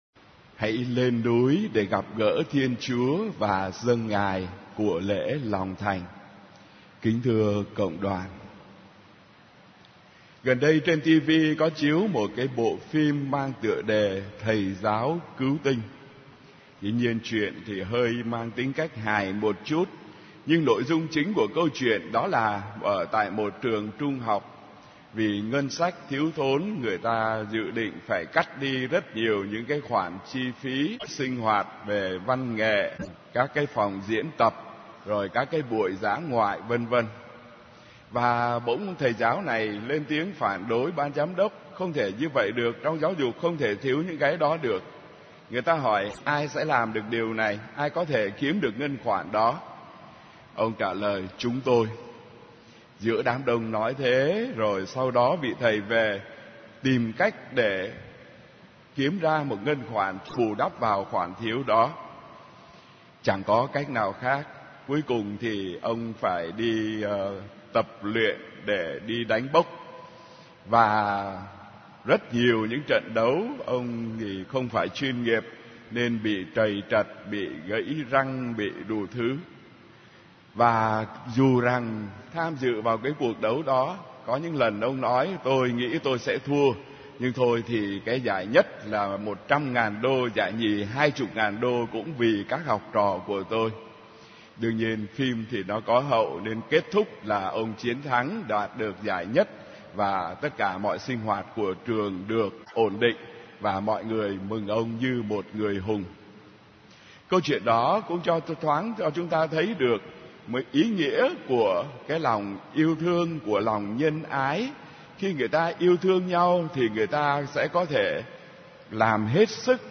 * Thể loại: Nghe giảng